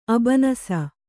♪ abanasa